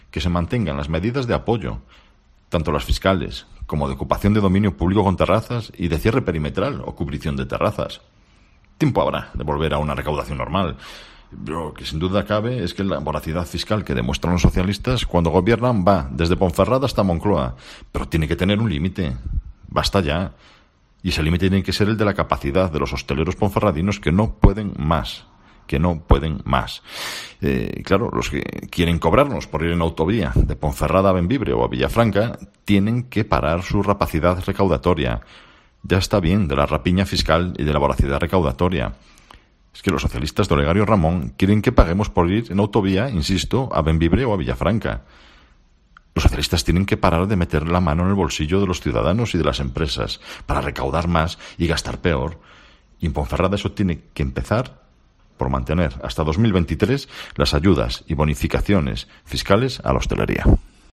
AUDIO: Escucha aquí las palabras del portavoz de los populares en la capital berciana, Marco Morala